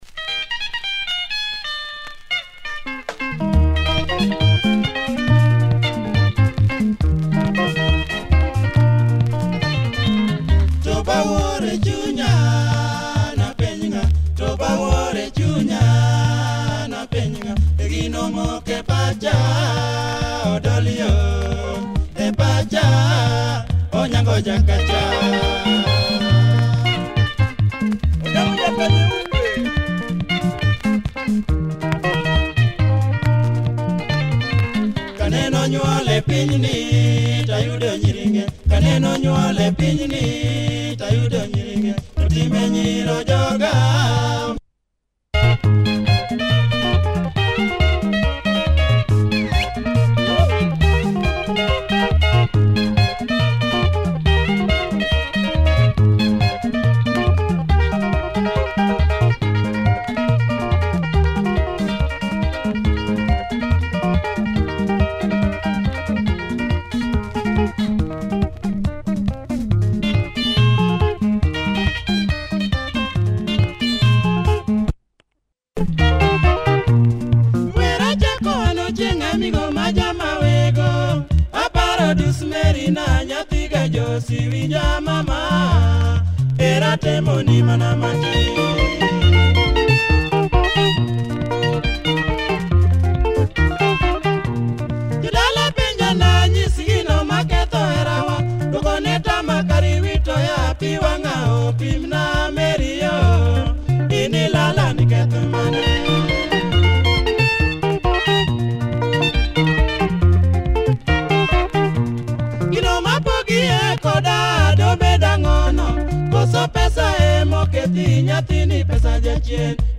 Quality Luo benga